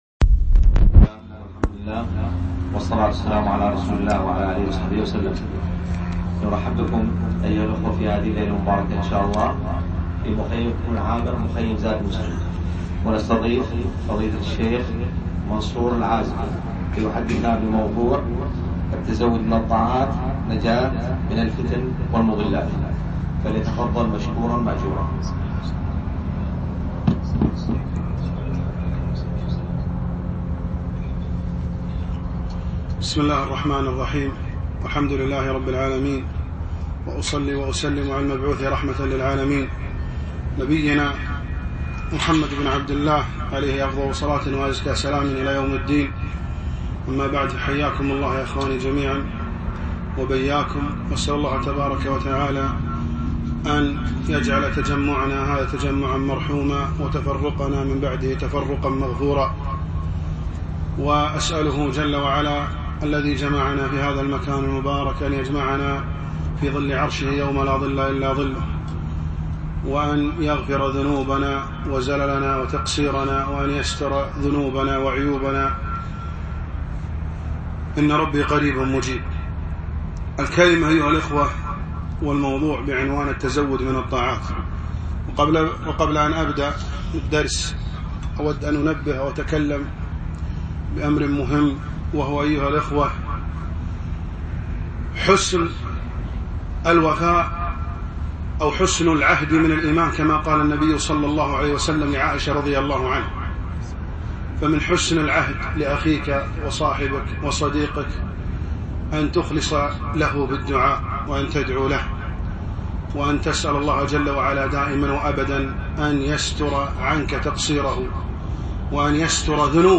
يوم الجمعة 21 ربيع الأول 1437هـ الموافق 1 1 2016م في ديوان مشروع زاد المسلم الوفرة